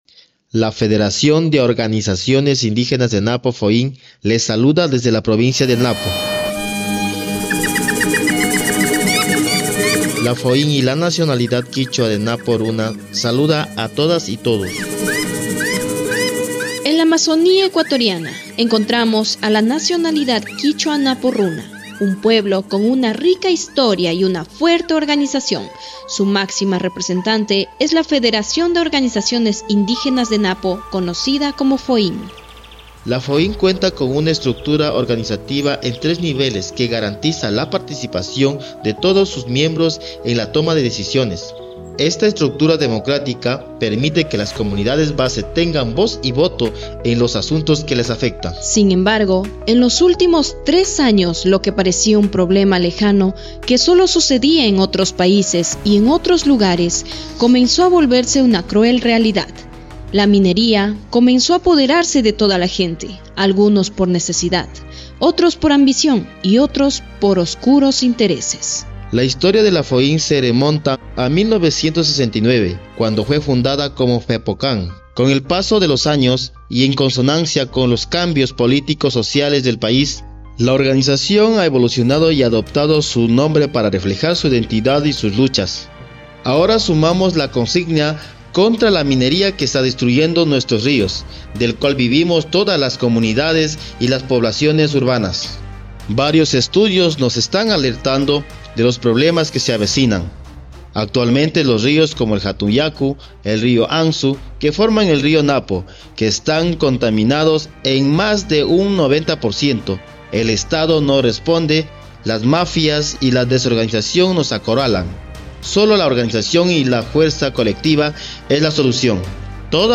Reportajes Radiales